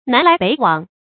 南来北往 nán lái běi wǎng 成语解释 有的从南往北，有的从北往南。